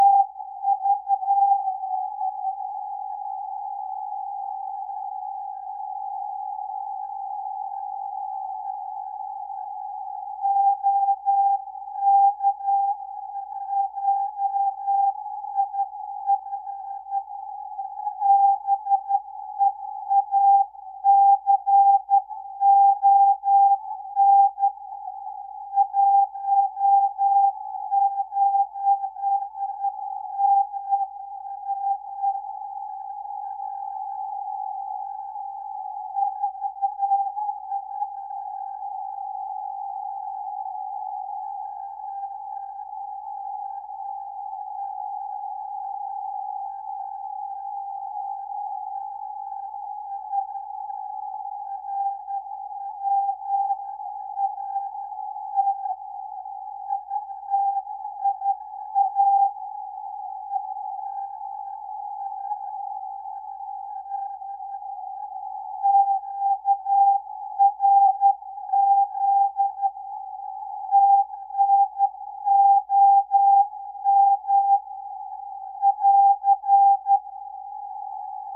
Na můj vkus bych však vypnul to DSP, které je zde k ničemu. Jen zaobluje hrany. Přesto však nahrávka je kvalitní.